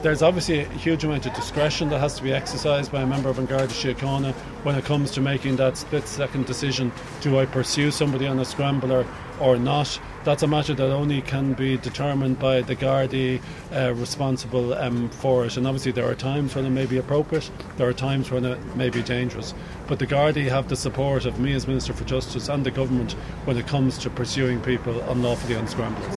Justice Minister Jim O’Callaghan says senior gardaí have the government’s full support: